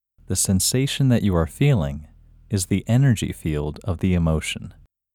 IN – Second Way – English Male 3